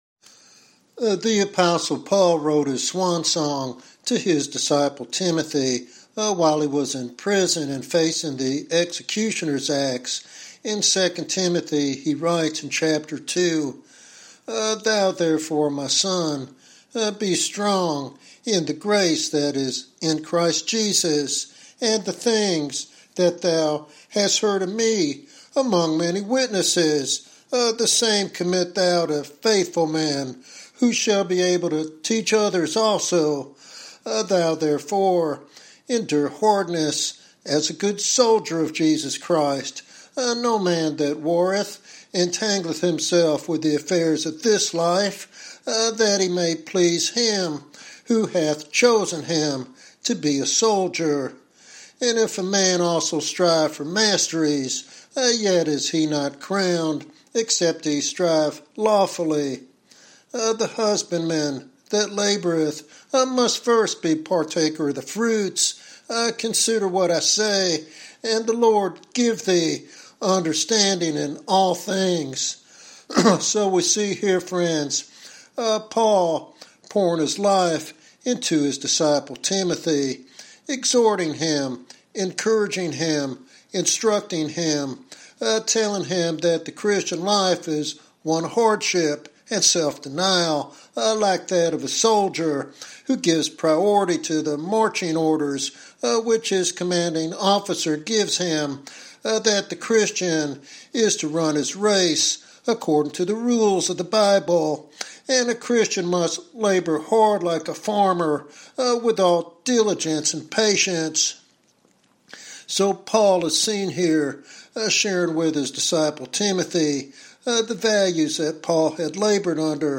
This sermon serves as a practical and spiritual guide for those called to gospel ministry.